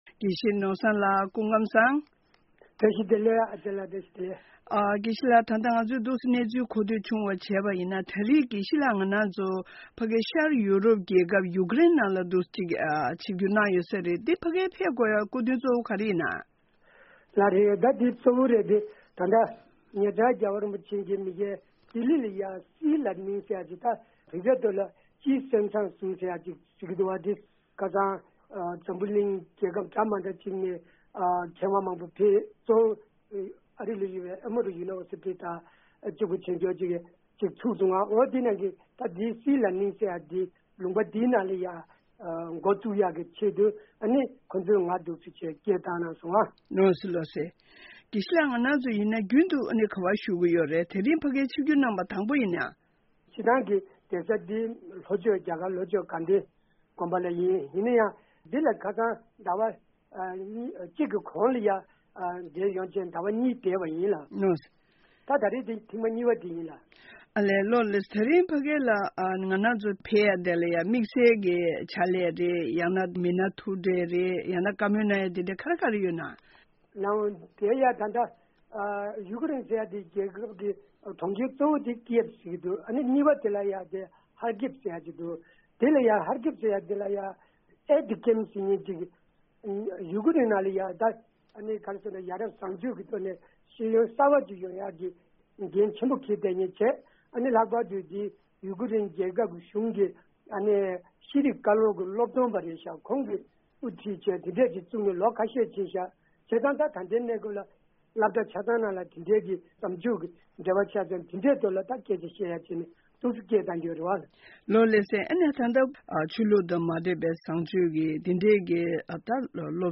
བཅའ་འདྲི་ཞུས་པ